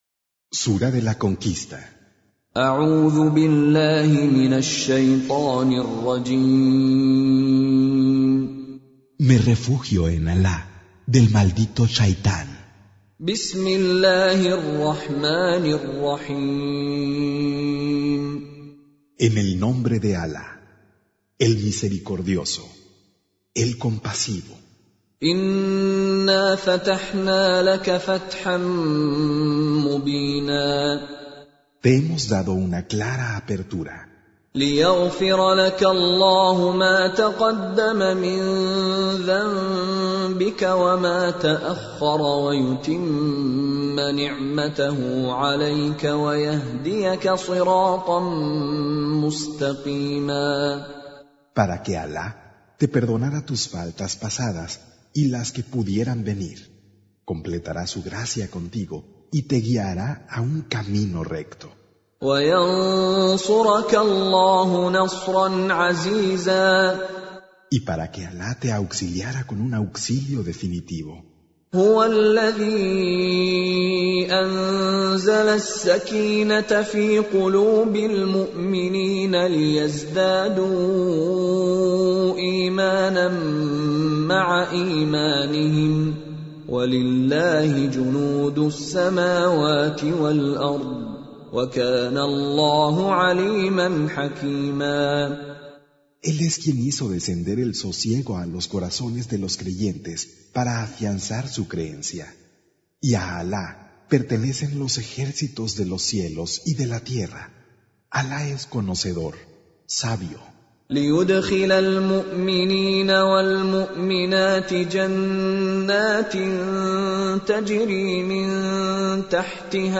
Surah Sequence تتابع السورة Download Surah حمّل السورة Reciting Mutarjamah Translation Audio for 48. Surah Al-Fath سورة الفتح N.B *Surah Includes Al-Basmalah Reciters Sequents تتابع التلاوات Reciters Repeats تكرار التلاوات